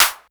Snare_07.wav